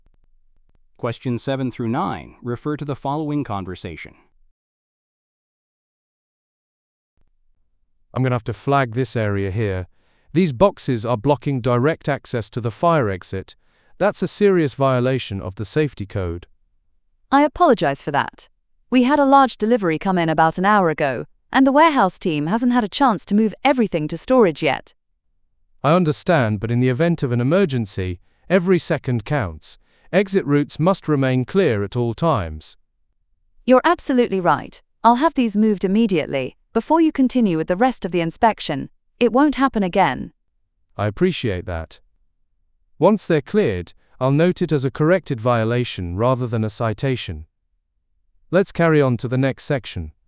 ※TOEICは、アメリカ/イギリス/オーストラリア/カナダ発音で出ます。